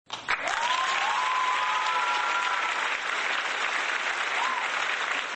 Applause 101
applause-101.mp3